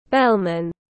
Bellman /ˈbel mæn/